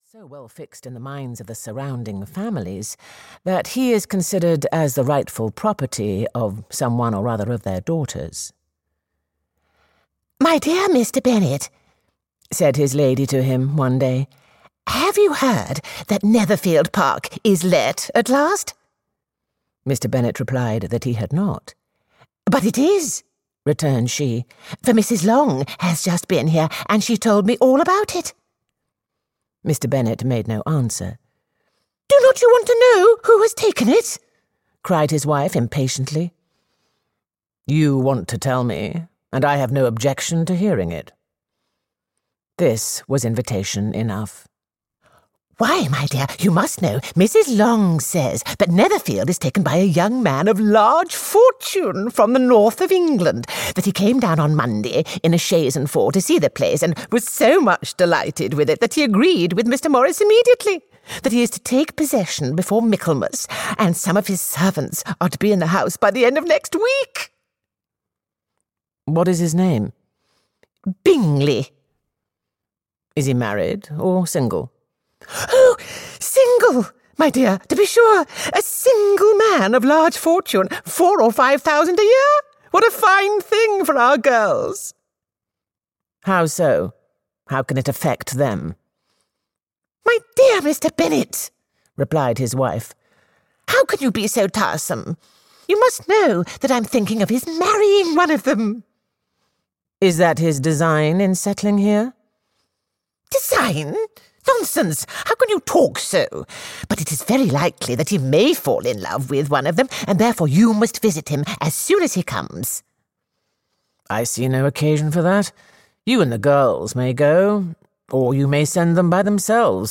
Pride and Prejudice (EN) audiokniha
Ukázka z knihy
The spotlight falls on Elizabeth, second eldest, who is courted by Mr Darcy though initially she is more concerned with the fate of her other sisters. This marvellous account of family life in Regency England is read with customary panache by Juliet Stevenson.